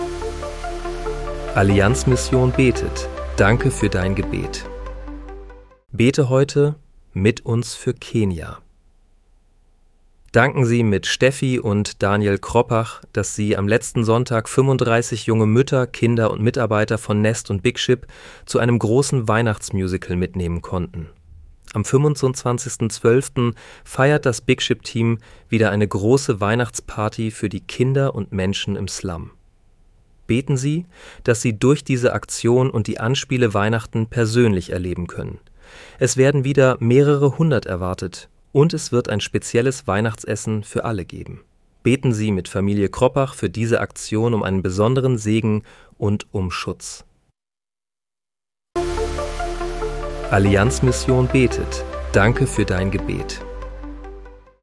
Bete am 24. Dezember 2025 mit uns für Kenia. (KI-generiert mit der